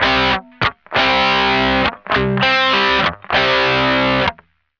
You can now be a one man band, keys marked with a "G" are electric guitar riffs, keys marked with a "B" are bass riffs, keys marked with a "D" are drum solos, mix and match and ROCK OUT!